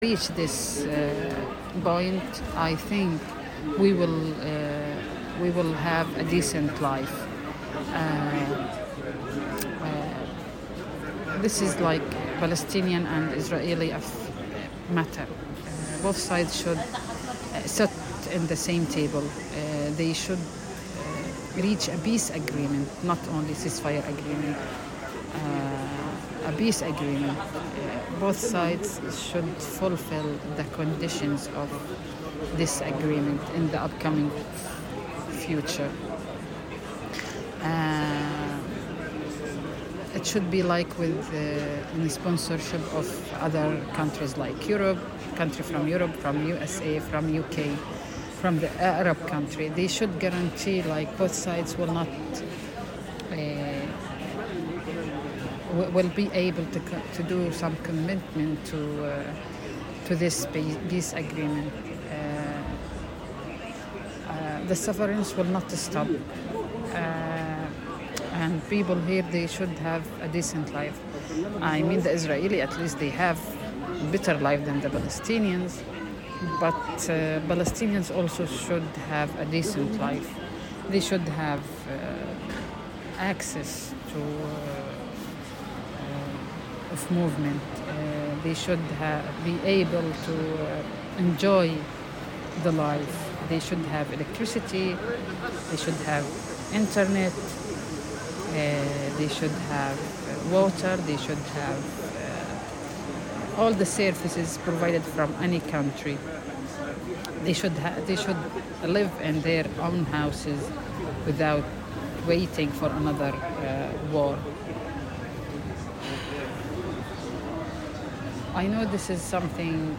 Live from Soundcamp: soundcamp radio - Radio With Palestine (Audio) Jan 07, 2025 shows Live from Soundcamp Live transmission by the Soundcamp Cooperative Play In New Tab (audio/mpeg) Download (audio/mpeg)